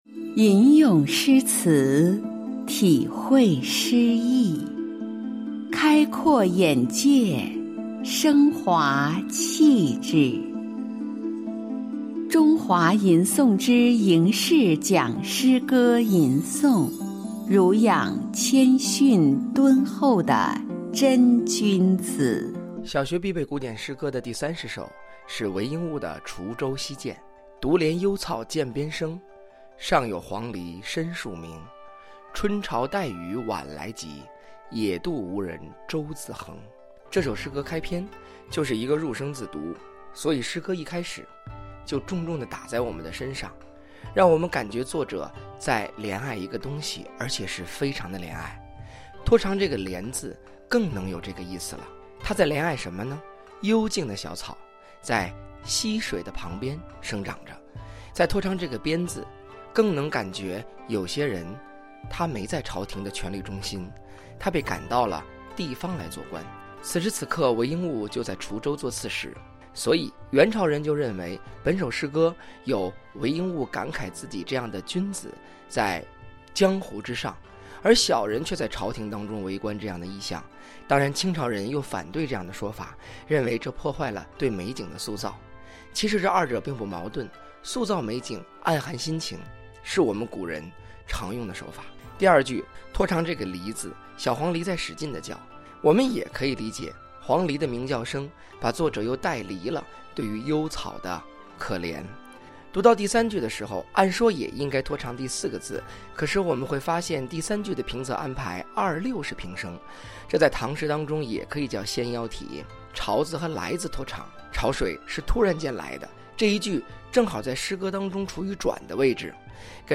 吟诵书写韦应物《滁州西涧》